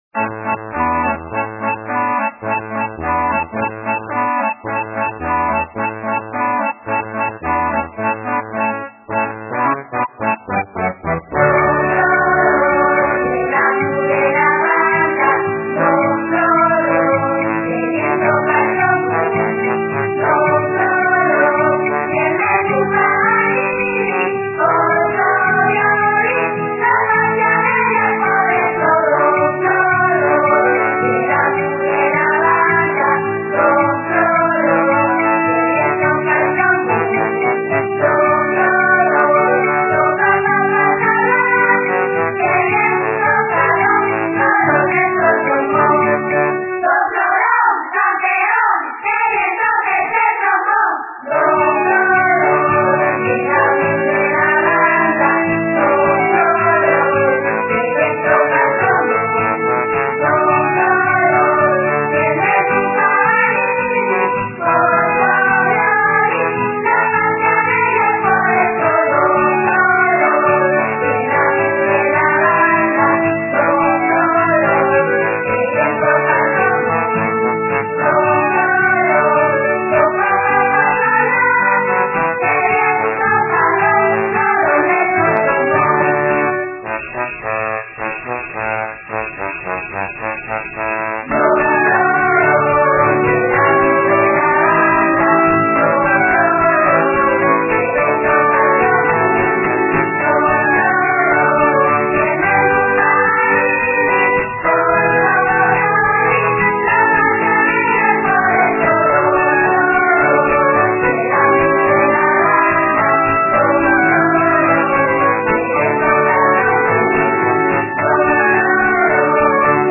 Son toos escolinos del C.P de Lieres. (Siero) La música
guitarres